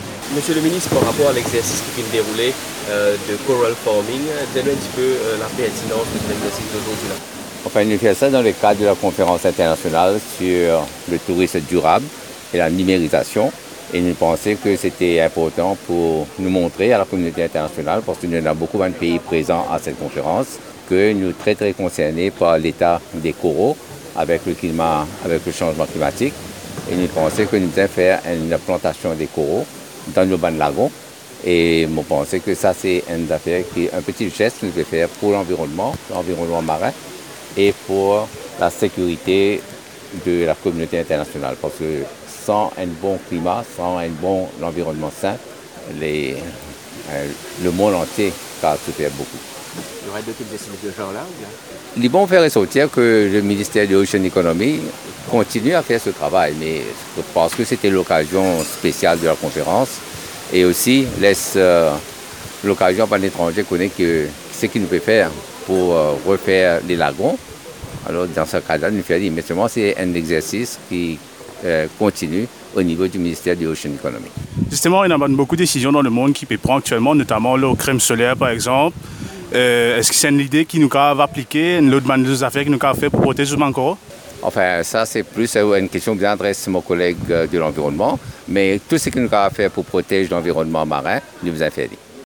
Propos du ministre du Tourisme, Anil Gayan, ce jeudi 24 mai, à Trou-aux-Biches. Il intervenait en marge de la conférence internationale sur le tourisme durable et la numérisation.